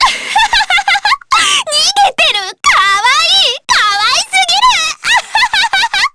Miruru_L-Vox_Skill2_jp.wav